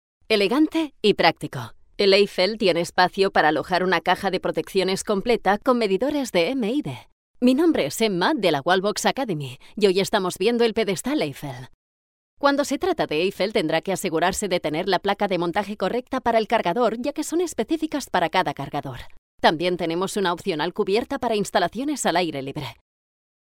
Neumann U87 + Studiobricks.
kastilisch
Sprechprobe: eLearning (Muttersprache):